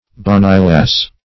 Search Result for " bonnilass" : The Collaborative International Dictionary of English v.0.48: Bonnilass \Bon"ni*lass`\ (b[o^]n"n[y^]*l[.a]s`), n. [Bonny + lass.] A "bonny lass"; a beautiful girl.